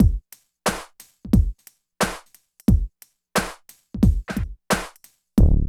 95 DRUM LP-R.wav